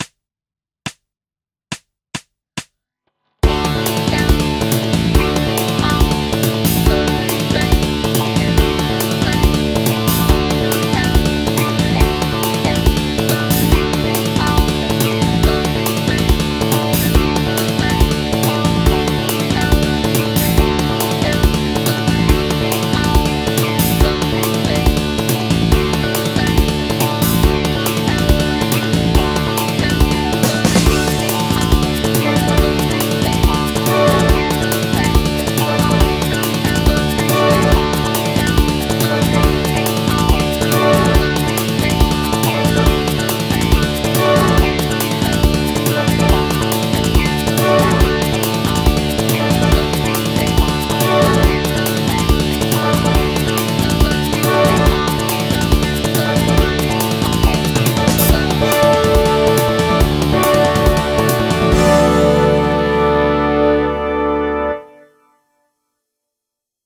ヒンズー・スケール ギタースケールハンドブック -島村楽器